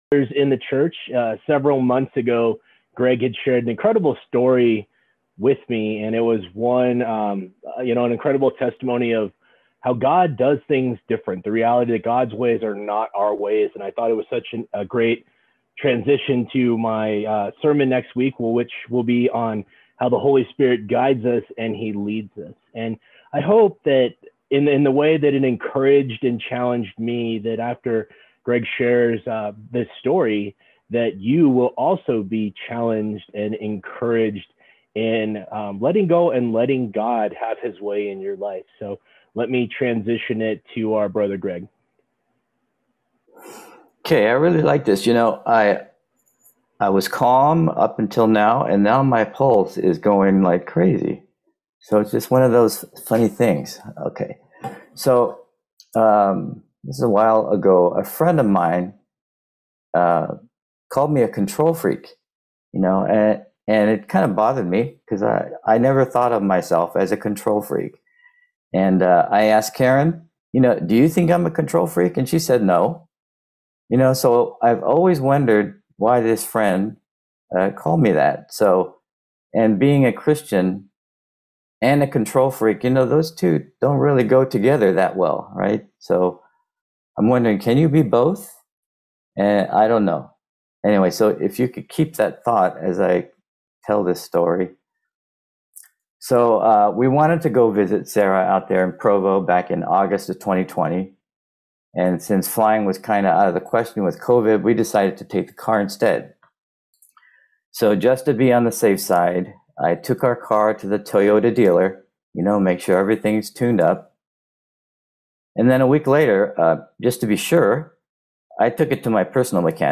July 11, 2021 Sunday Worship Service